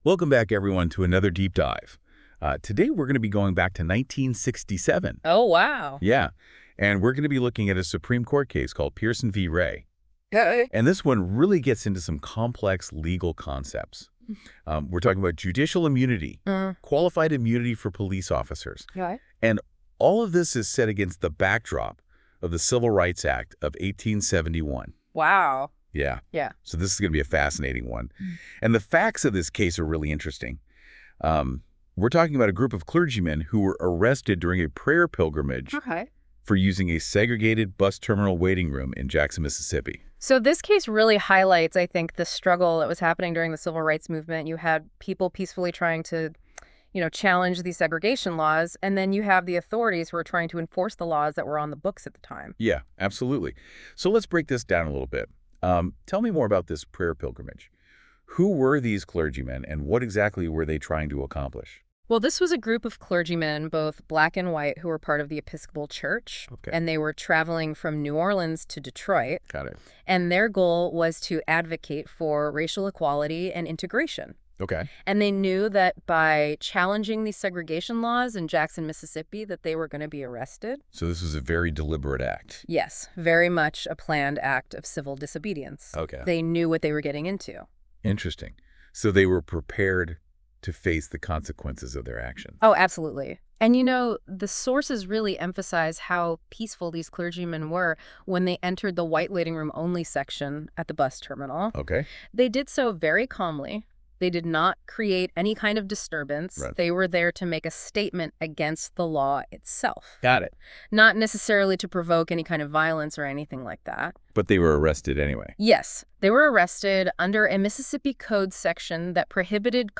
Listen to an audio breakdown of Pierson et al. v. Ray et al..